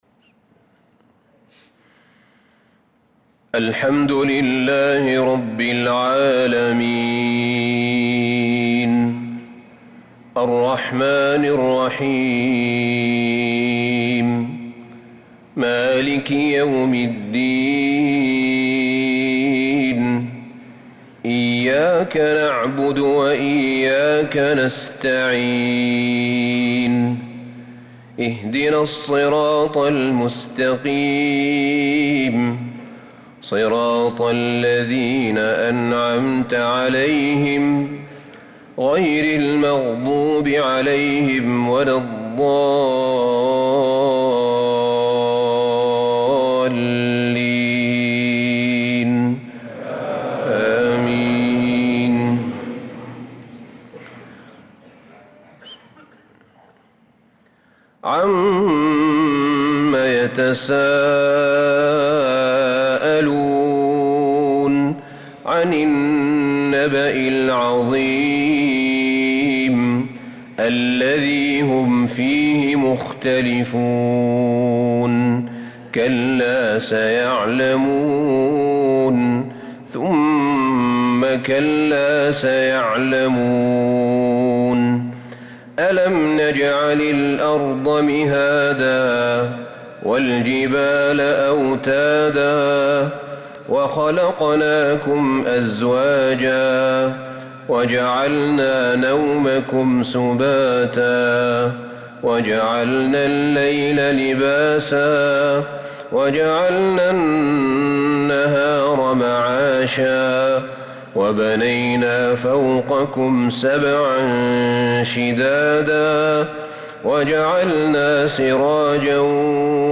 صلاة العشاء للقارئ أحمد بن طالب حميد 20 جمادي الأول 1446 هـ